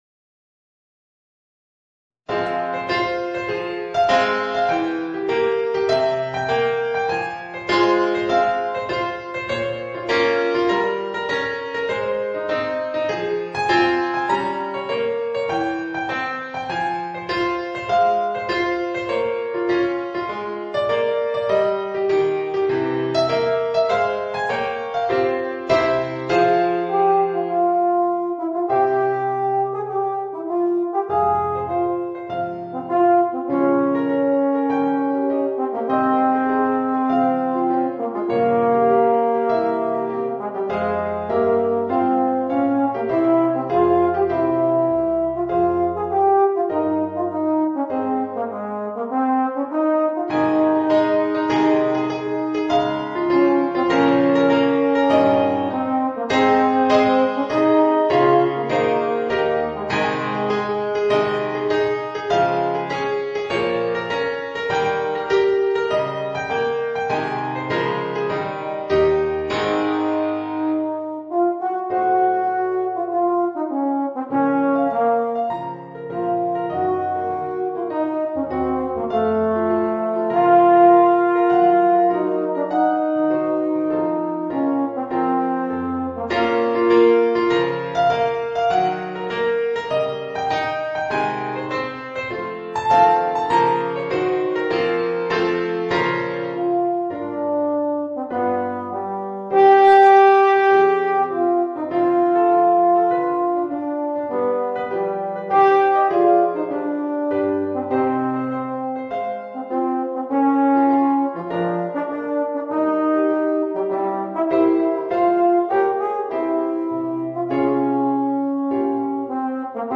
Voicing: Euphonium and Organ